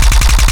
Added more sound effects.
GUNAuto_RPU1 Loop_01_SFRMS_SCIWPNS.wav